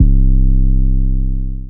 808 (No Heart).wav